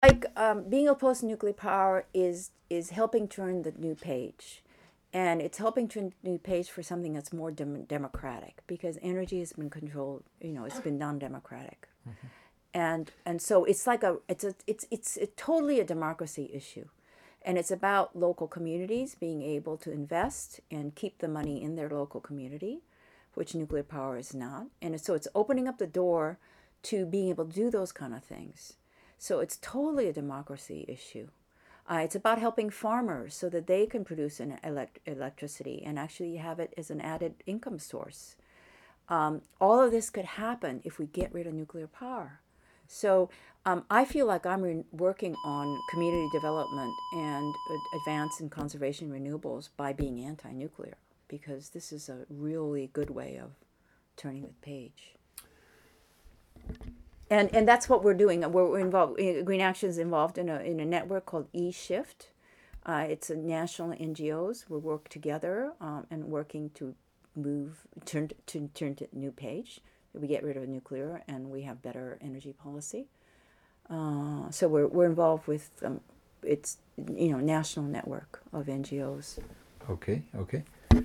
Available are recordings -Songs, Interviews of scientists, farmers, activists and misc sounds at misc public demonstrations including Chernobyl, Ukraine & Fukushima, Japan.  Here is a unedited selection.